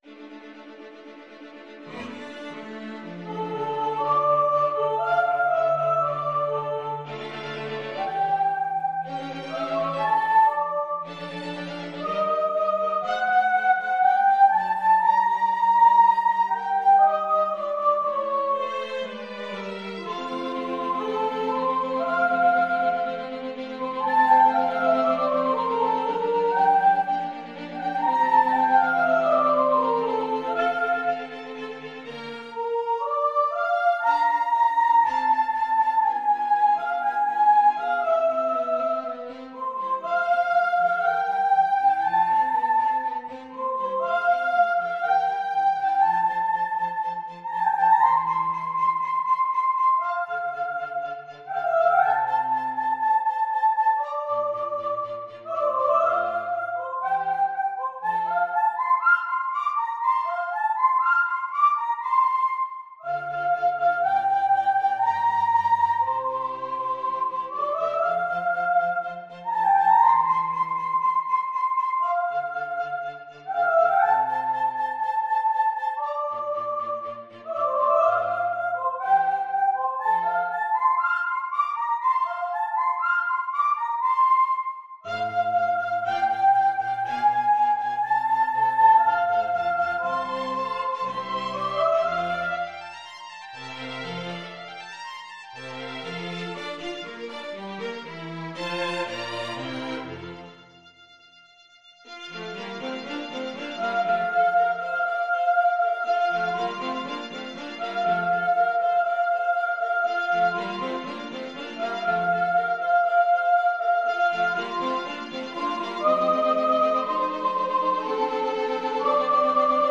Classical
Soprano Violin 1 Violin 2 Viola Cello